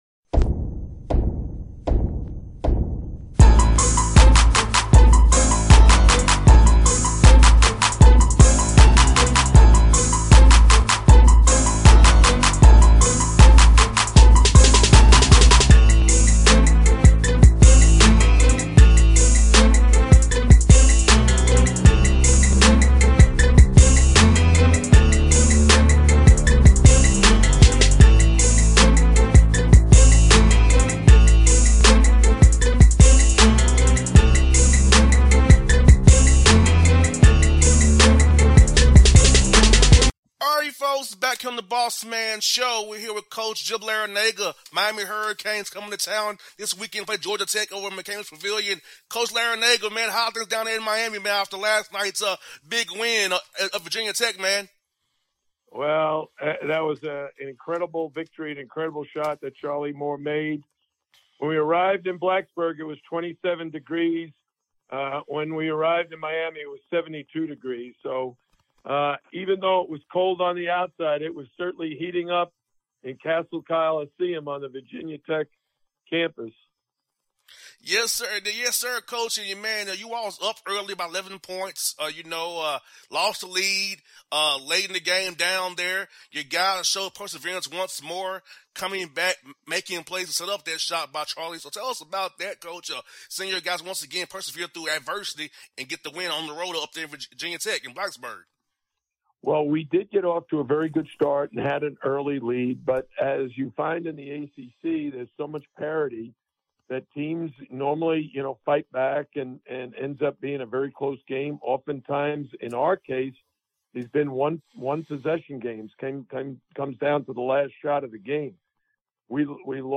Jim Larranaga Interview